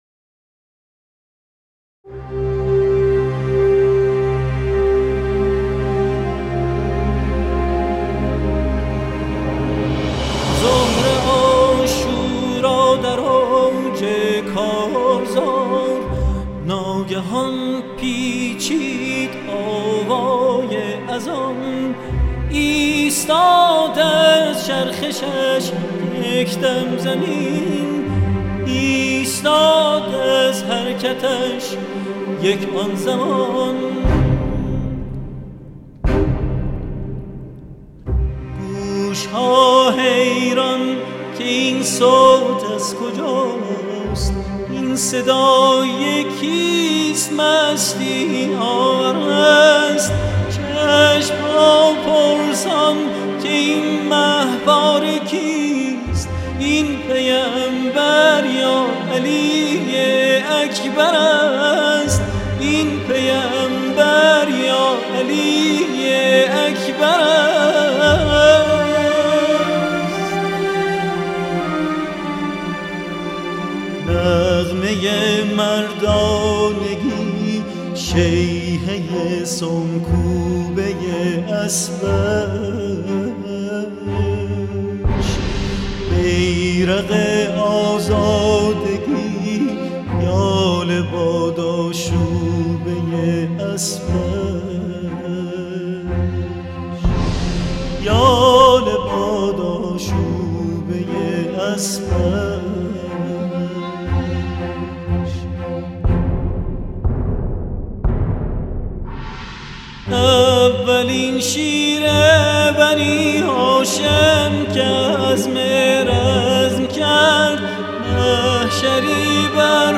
از خوانندگان موسیقی ایرانی